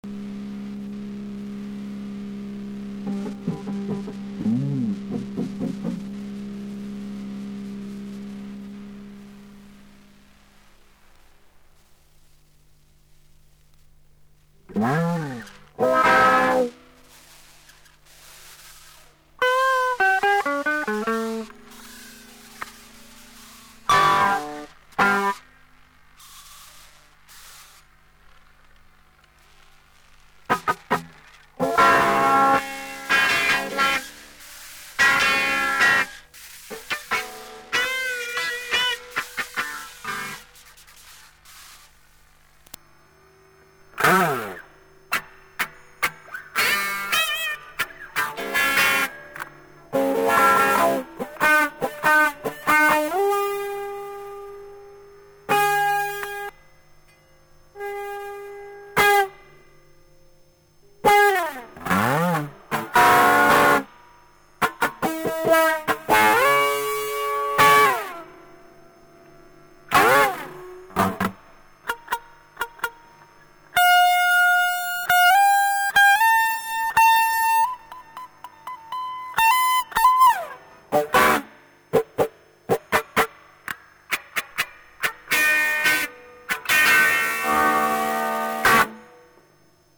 パチジャリンッパチ Tape と Spring の スイッチング 煩いったらぁりゃしなぃ困ったのどうすんか。